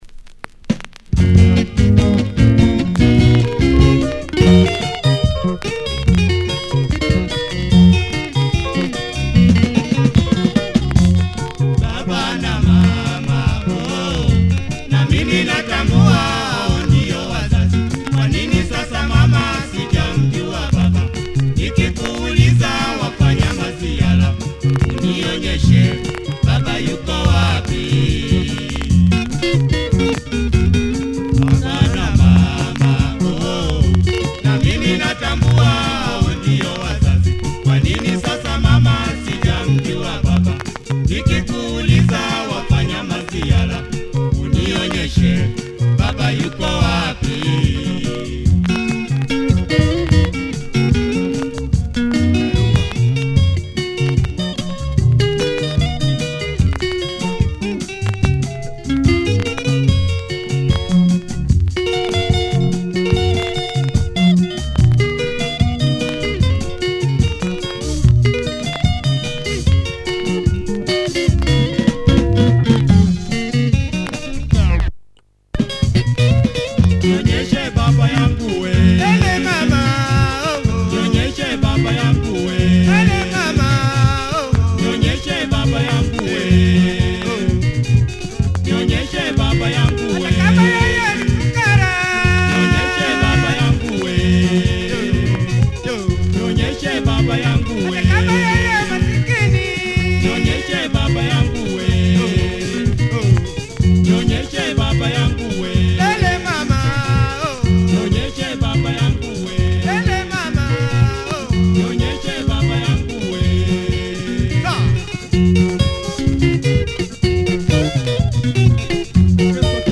Nice guitar drive in this Tanzania? rumba track.